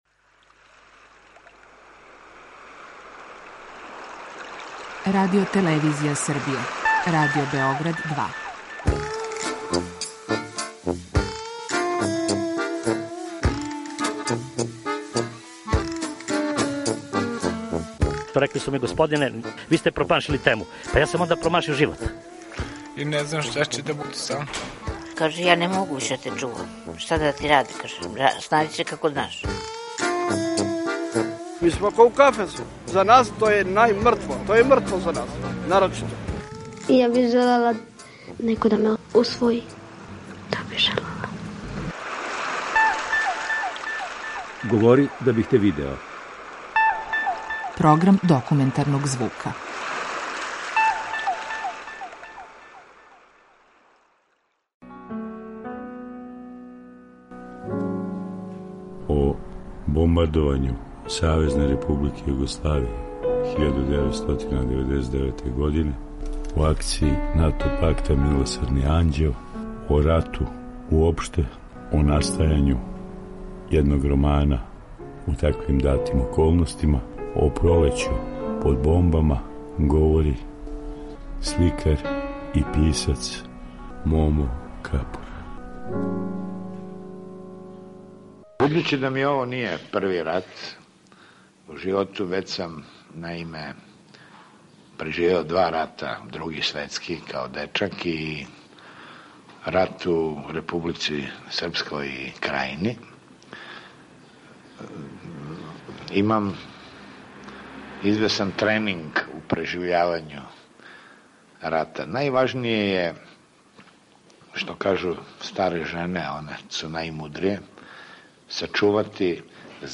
Документарни програм
О НАТО агресији 1999. године овако је говорио писац и сликар Момо Капор.